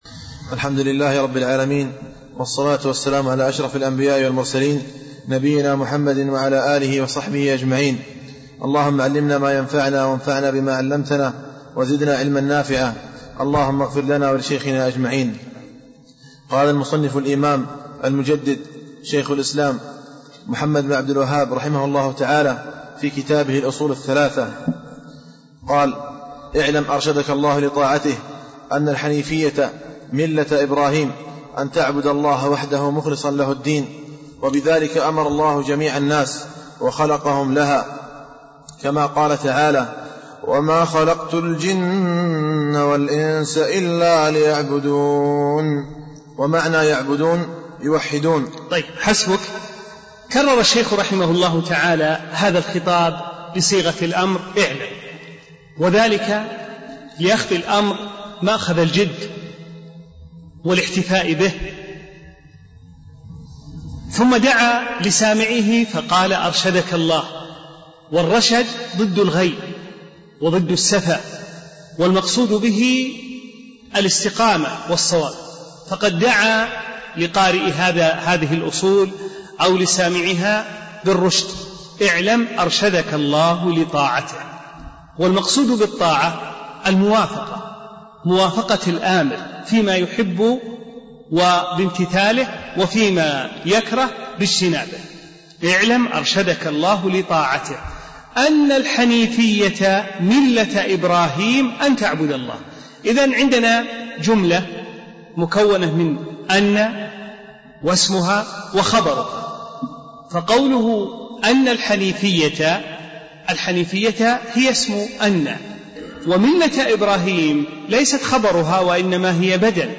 درس اعلم أرشدكَ الله اعلم أرشدكَ الله 0 | 511 تحميل الملف الصوتى تحميل الدرس pdf من صفحة : (46)، قوله: ((اعلم -ارشدك الله- .....)